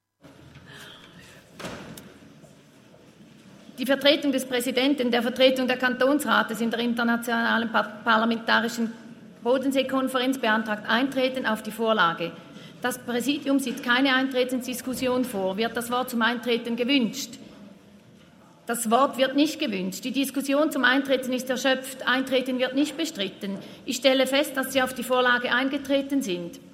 14.6.2023Wortmeldung
Session des Kantonsrates vom 12. bis 14. Juni 2023, Sommersession